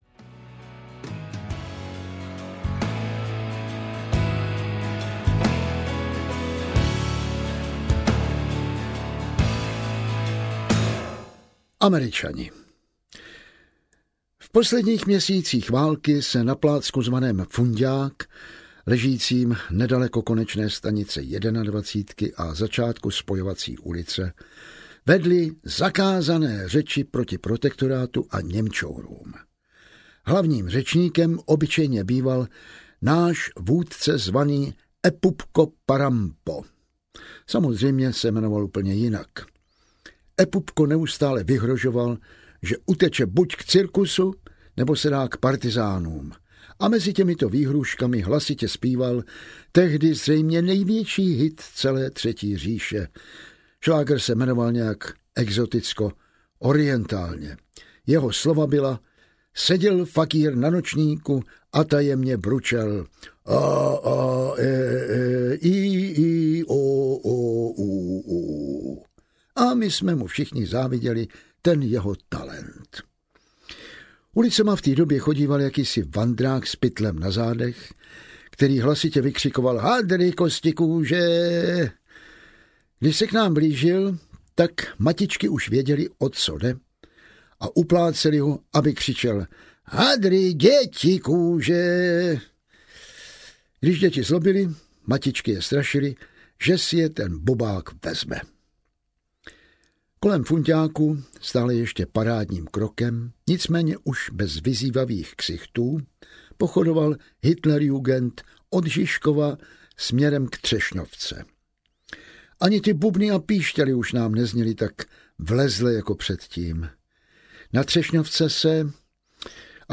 Tak kde jsou ti Američani? audiokniha
Ukázka z knihy
tak-kde-jsou-ti-americani-audiokniha